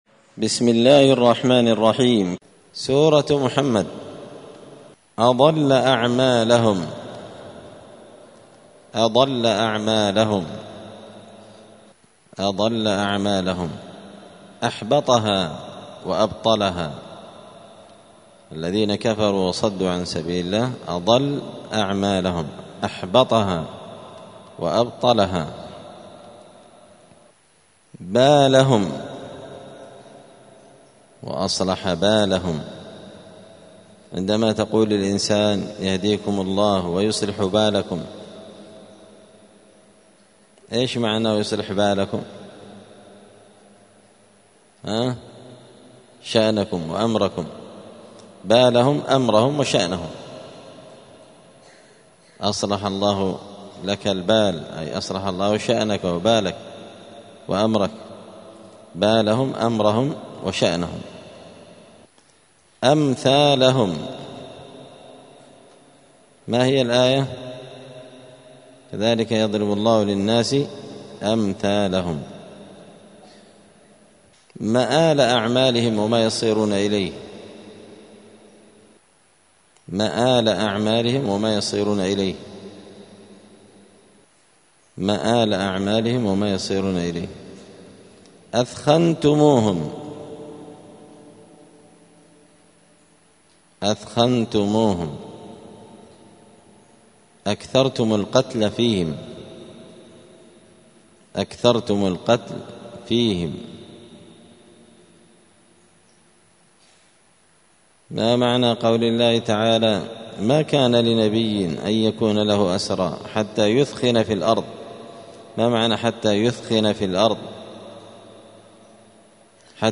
*(جزء الأحقاف سورة الأحقاف الدرس 204)*